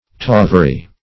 Tawery \Taw"er*y\, n. A place where skins are tawed.